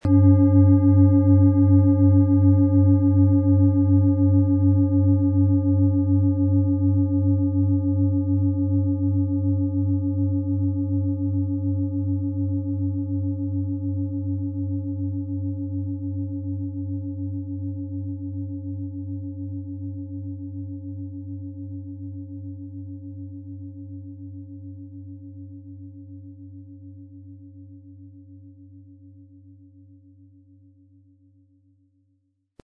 Tibetische Universal-Bauch-Schulter-Herz- und Kopf-Klangschale, Ø 25,5 cm, 1600-1700 Gramm, mit Klöppel
Beim Speichern des Tones der Schale haben wir sie angespielt, um herauszubekommen, welche Körperregionen sie zum Schwingen bringen könnte.
den einzigartigen Klang und das außerordentliche, bewegende Schwingen der traditionsreichen Handarbeit.
Für jemanden dem Klang vor Optik geht eine schöne Gelegenheit eine tibetische Klangschale in stattlicher Größe und mit tiefem Klang zu erwerben.
MaterialBronze